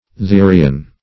eutherian - definition of eutherian - synonyms, pronunciation, spelling from Free Dictionary